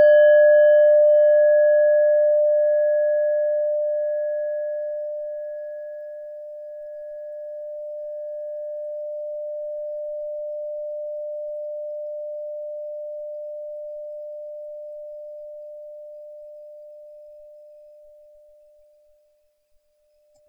Klangschale Nepal Nr.5
Klangschale-Gewicht: 610g
Klangschale-Durchmesser: 11,9cm
(Ermittelt mit dem Filzklöppel oder Gummikernschlegel)
klangschale-nepal-5.wav